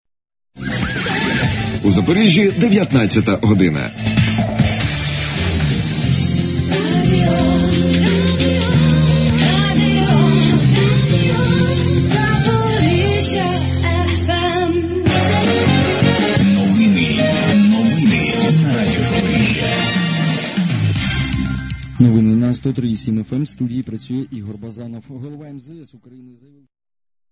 позивні 2012 р.